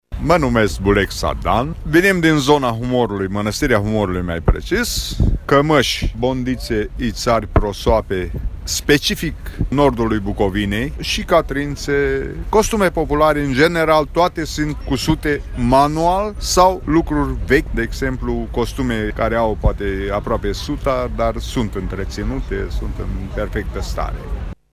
Artiști populari din toată țara la Târgul meșteșugăresc de la Tg.Mureș
Unul dintre artiștii populari a venit din nordul Bucovinei cu cămăși brodate, prosoape, ștergare și ițari: